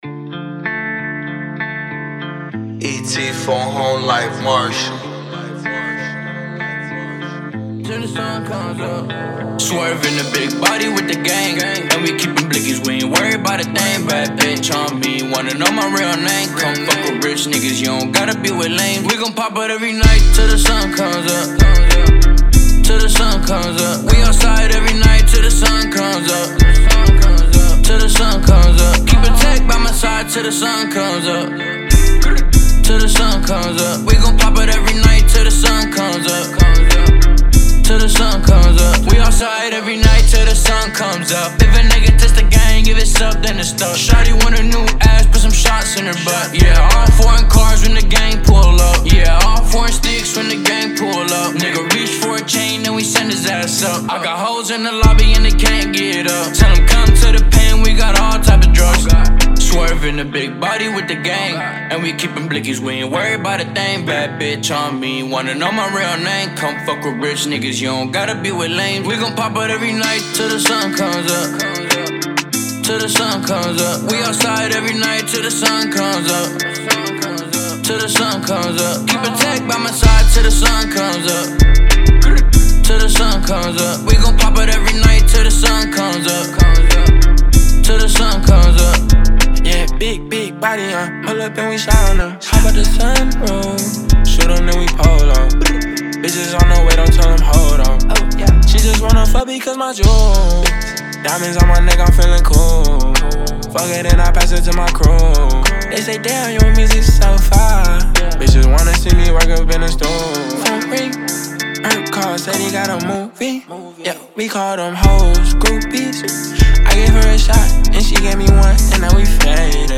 это энергичная трек в жанре хип-хоп